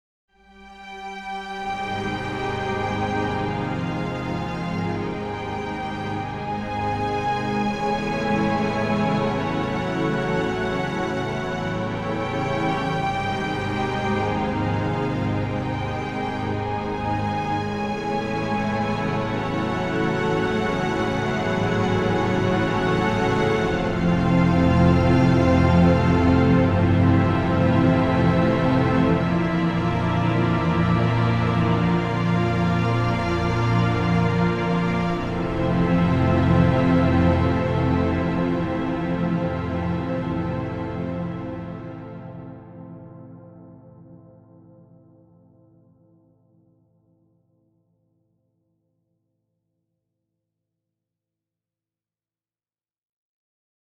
Orchestral Strings, Warm, A
cinematic Cinematic music Music orchestra Orchestra orchestral Orchestral sound effect free sound royalty free Memes